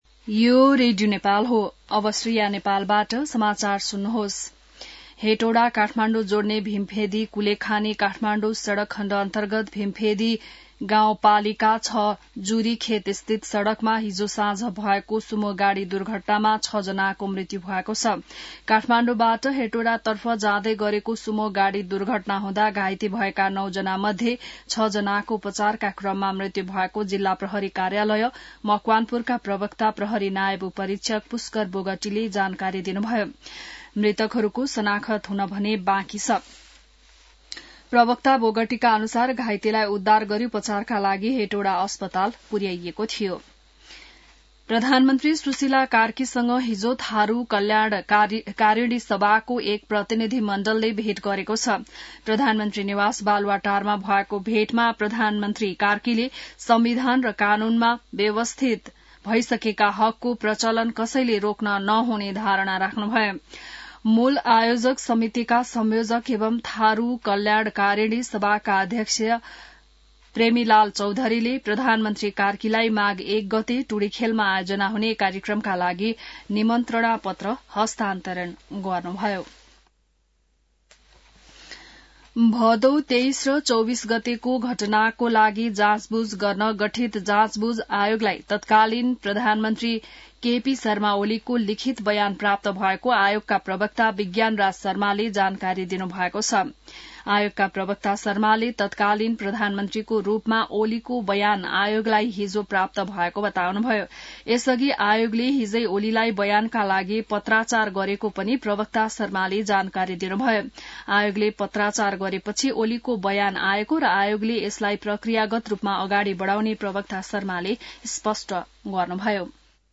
बिहान ६ बजेको नेपाली समाचार : २१ पुष , २०८२